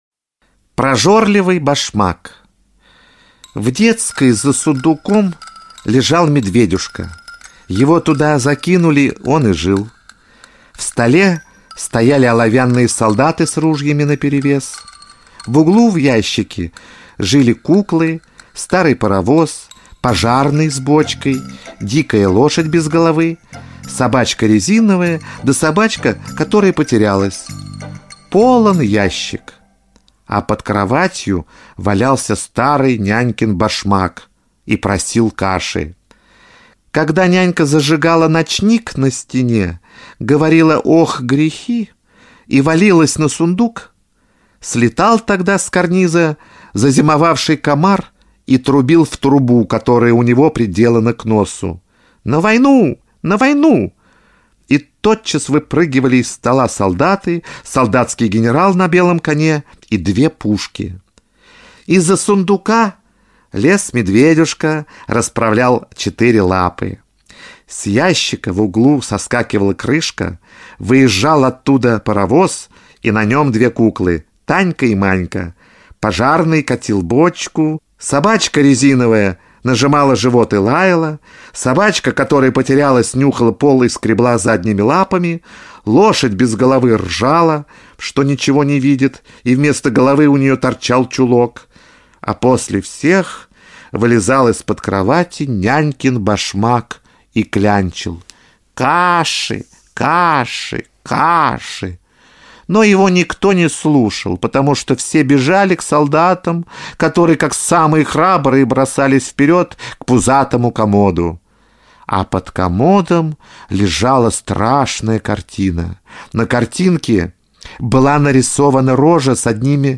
Прожорливый башмак – Толстой А.Н. (аудиоверсия)
Аудиокнига в разделах